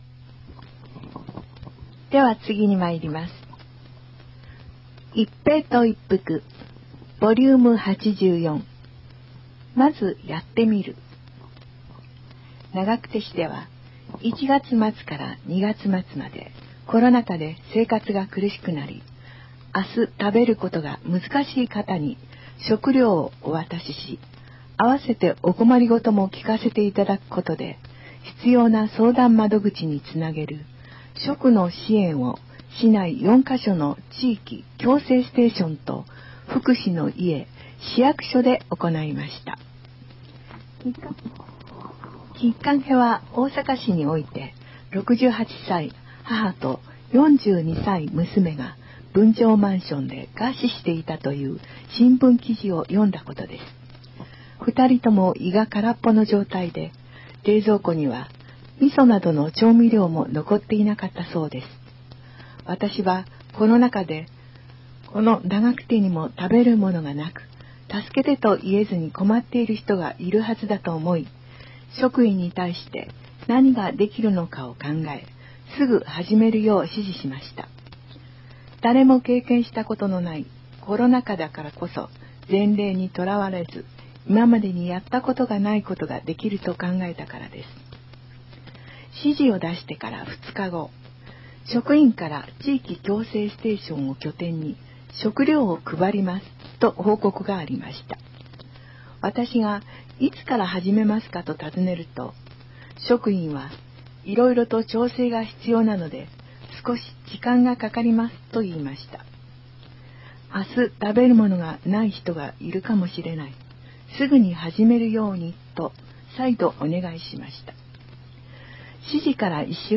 平成29年8月号から、ボランティア団体「愛eyeクラブ」の皆さんの協力により、広報ながくてを概要版として音声化して、ホームページ上で掲載しています。
• 音声ファイルは、カセットテープに吹き込んだものをMP3ファイルに変換したものです。そのため、多少の雑音が入っています。